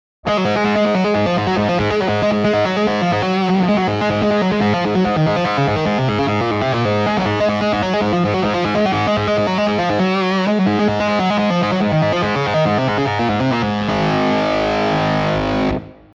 Synth-esque (just octave)
Synthesque.wav-just-OCT.mp3